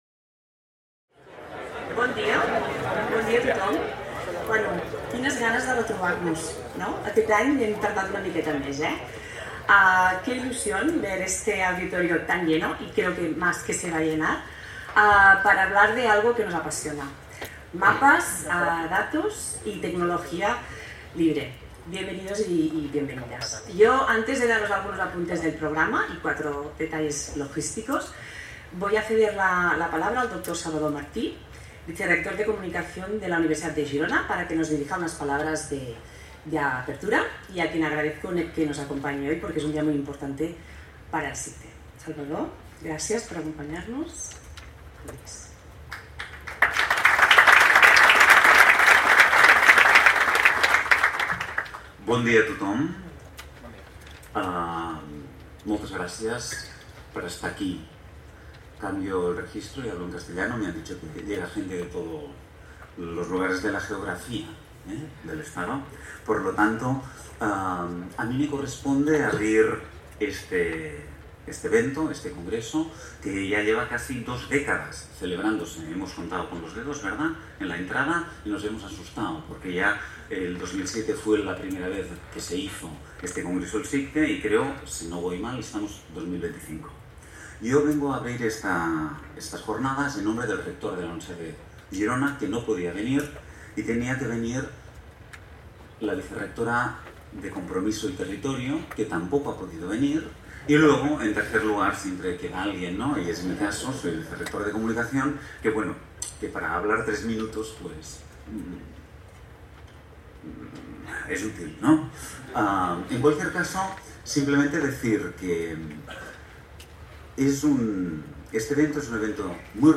Discurs d'obertura de les Jornades